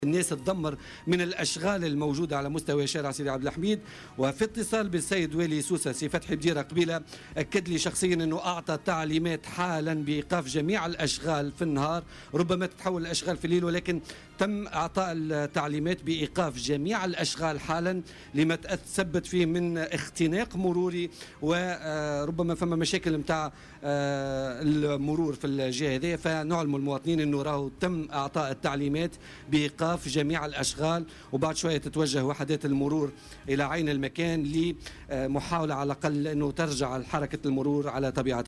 أكد والي سوسة، فتحي بديرة في تصريح اليوم الخميس ل"الجوهرة أف أم" أنه أعطى تعليماته لإيقاف الأشغال في منطقة سيدي عبد الحميد خلال النهار.